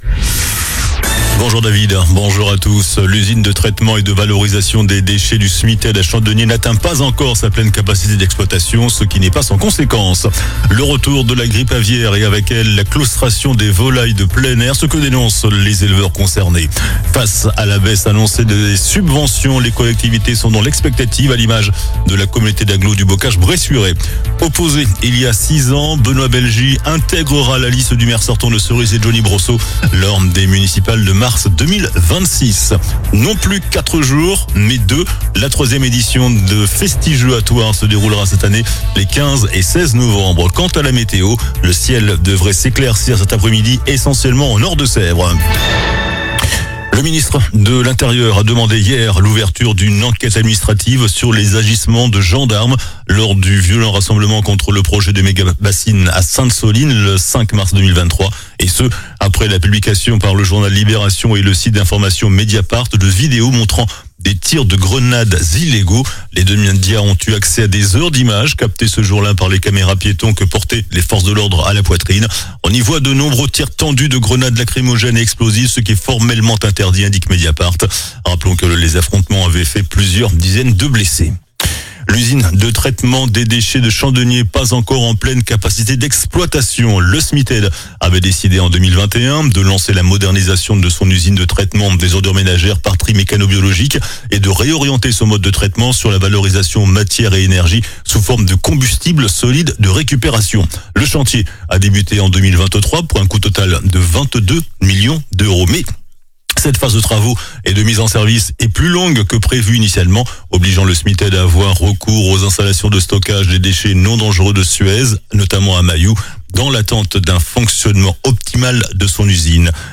JOURNAL DU JEUDI 06 NOVEMBRE ( MIDI )